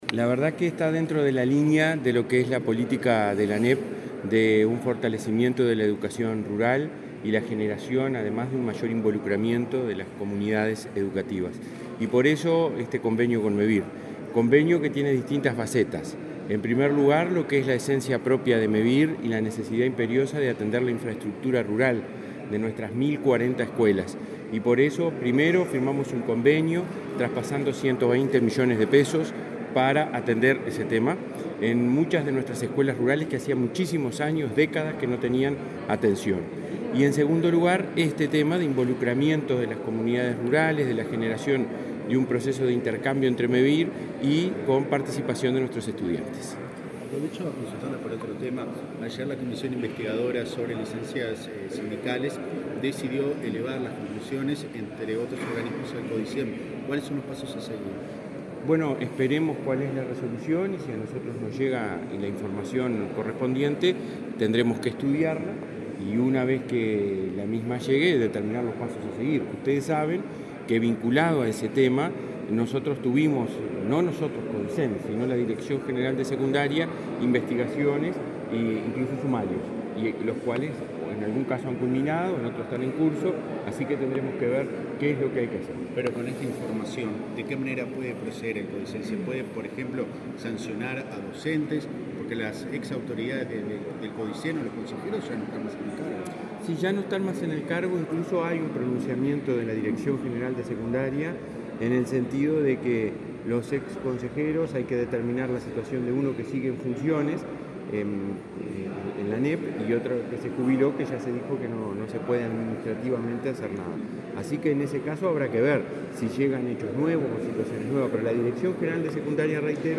Declaraciones a la prensa del presidente de la ANEP, Robert Silva
Este martes 7, la Administración Nacional de Educación Pública (ANEP), Mevir y el Ministerio de Ambiente lanzaron el concurso Ilustra tu Comunidad, cuyo objetivo es promover la reflexión acerca del desarrollo del sentido de pertenencia desde la sustentabilidad y mediante el arte. Luego, el presidente de la ANEP, Robert Silva, dialogó con la prensa.